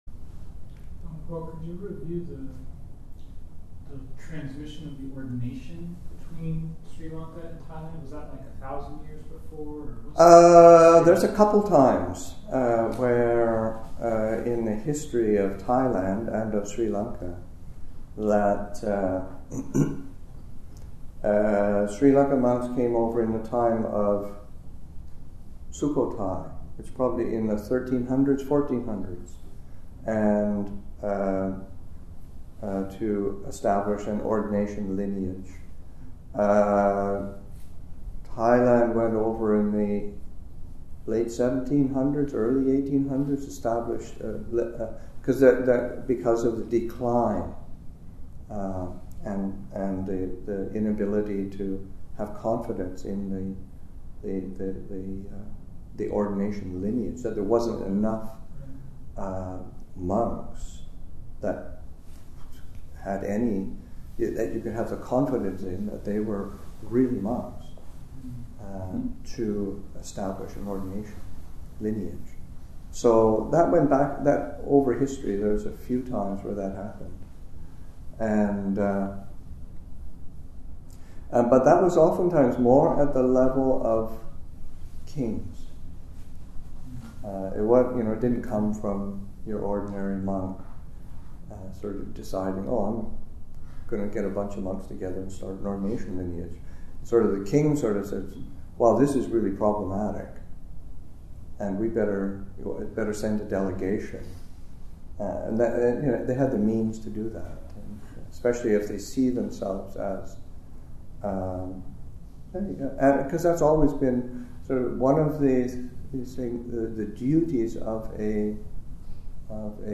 Abhayagiri Buddhist Monastery in Redwood Valley, California